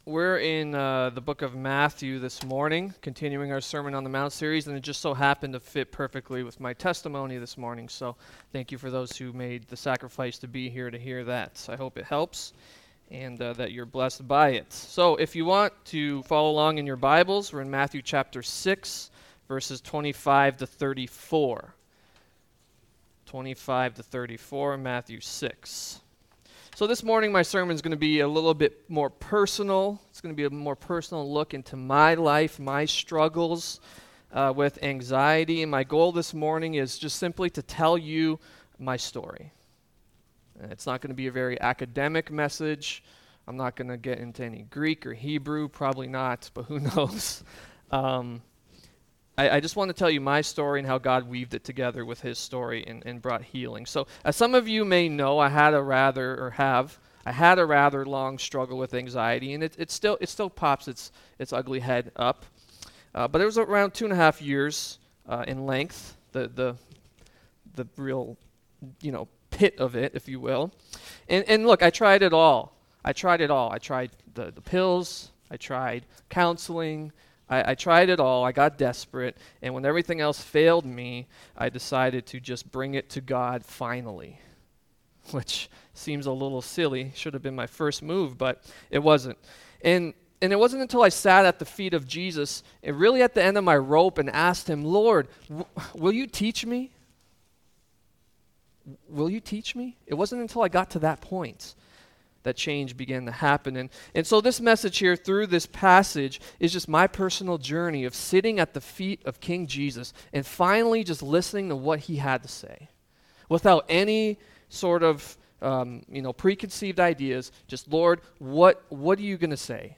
Date March 18, 2018 Topic Anxiety , Fear Series The Kings Edict: Studies in the Sermon on the Mount!